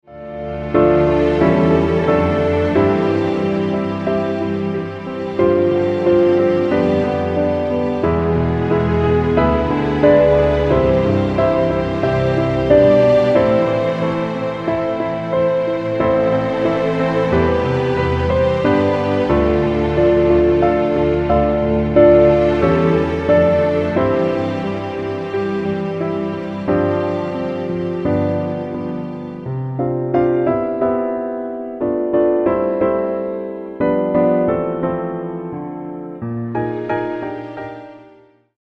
Piano - Strings - Low